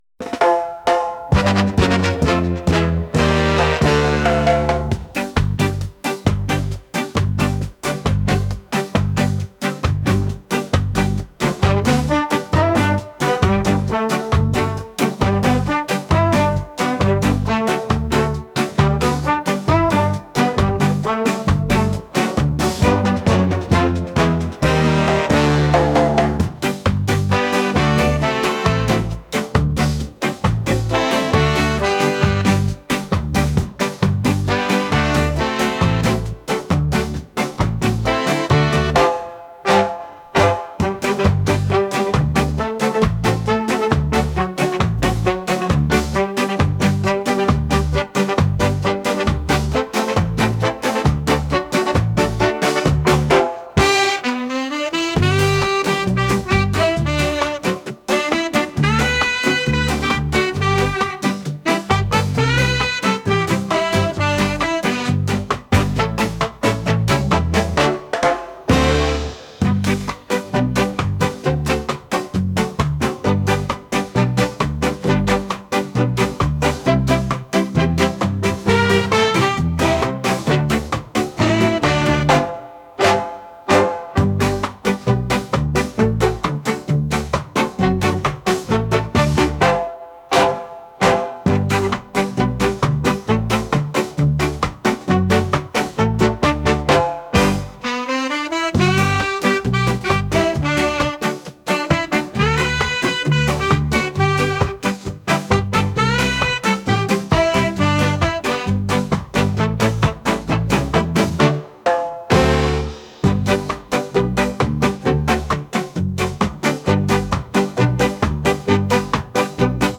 reggae | ska